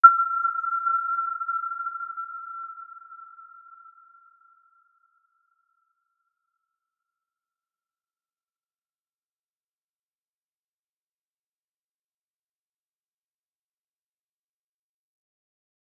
Aurora-G6-mf.wav